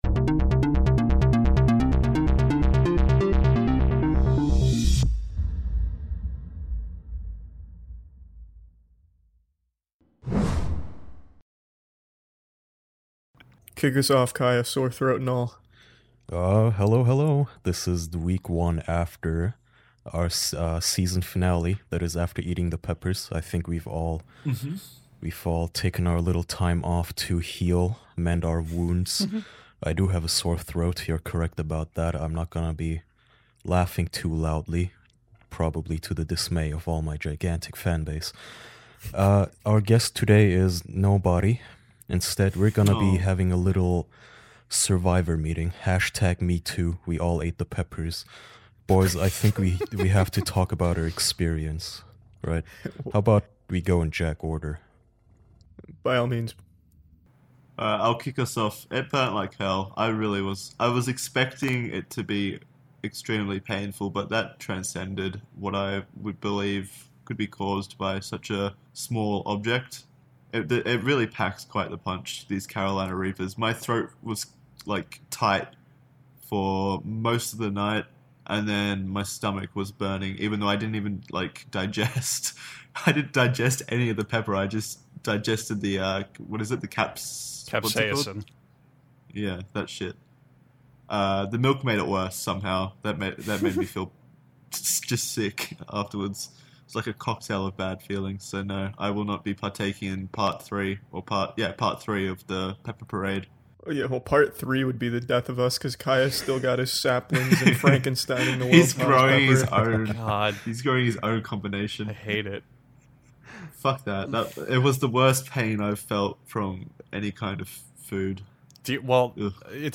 Four close man friends gather around to discuss arthritis.